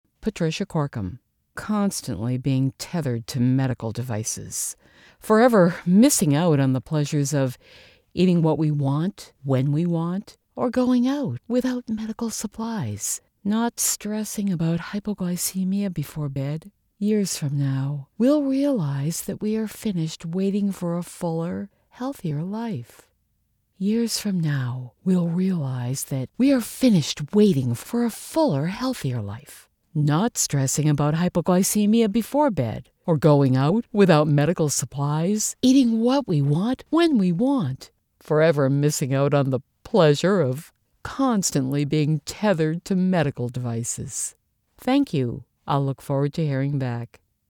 Conversational & engaging VO for commercial, narration & other story-telling projects.
Warm never whining.
Middle Aged